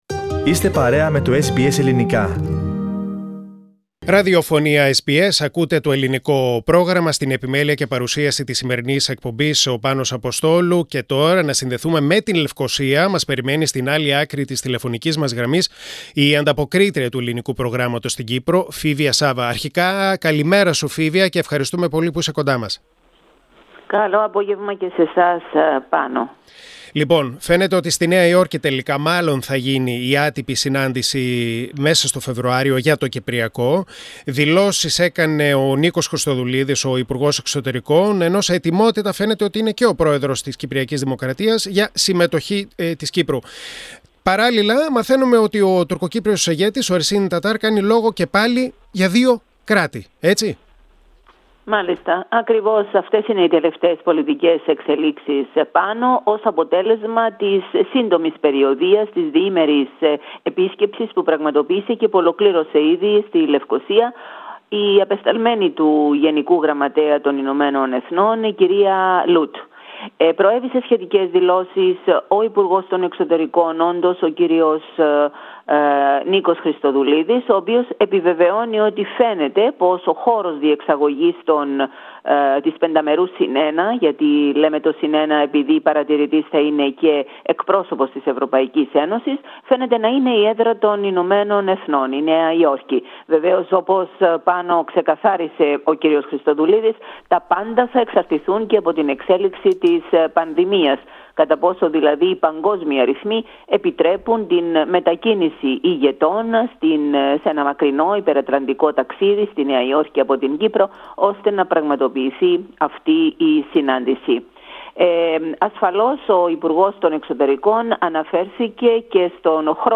ολόκληρη την ανταπόκριση